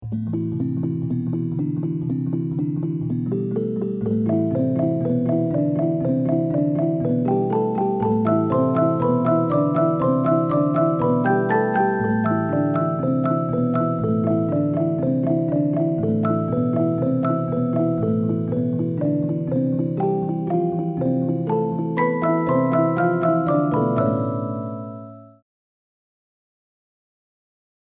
While learning to use the software, I wrote this little tune. Originally intended for flute, french horn and trombone (the instruments my siblings and I played in school band) I liked the way it sounded as a music box.
Music box.mp3